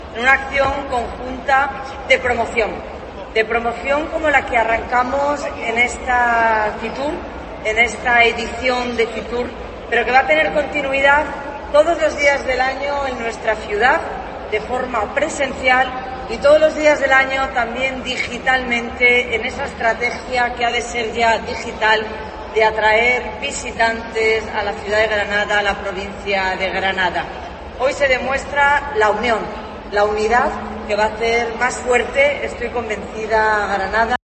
Marifrán Carazo, alcaldesa de Granada